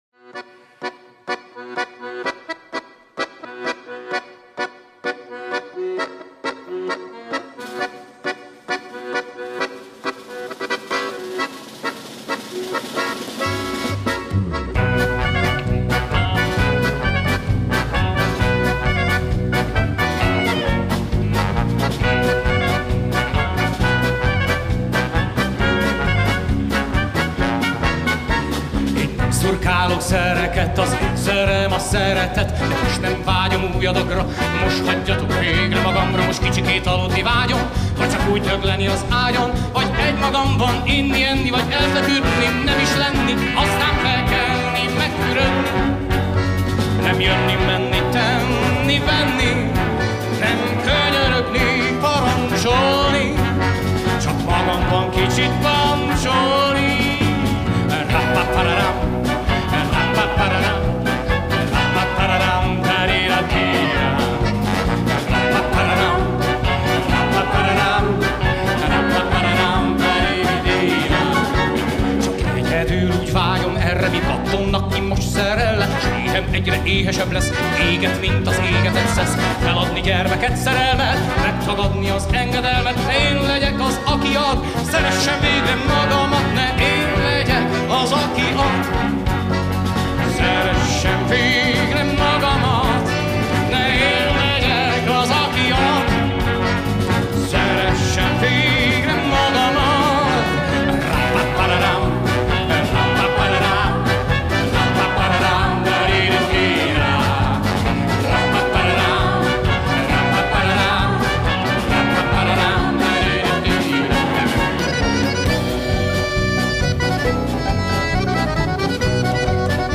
egy-egy daluk is fölcsendül